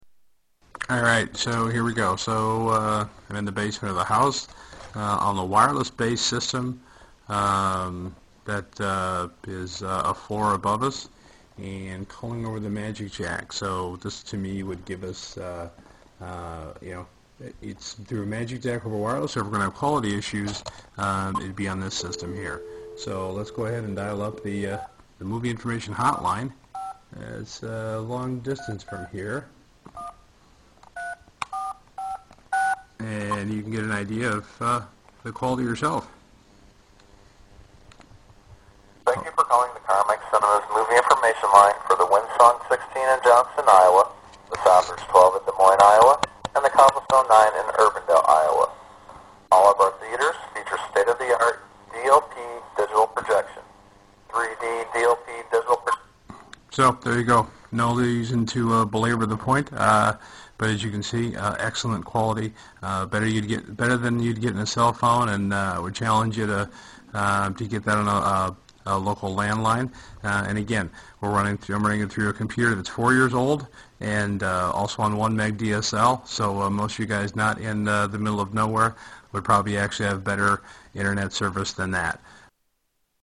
MagicJack Quality Test